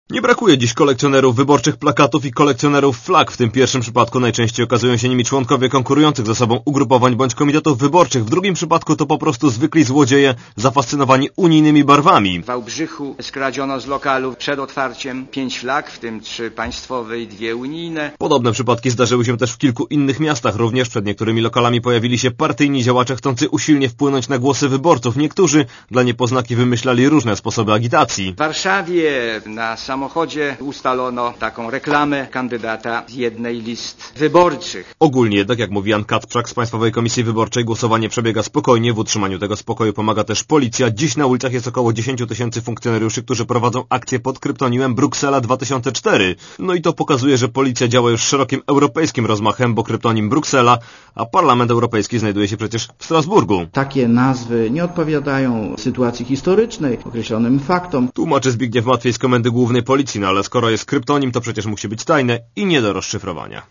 Relacja reportera Radia ZET Według danych PKW, głosowanie odbywa się w ponad 25 tys. komisjach obwodowych.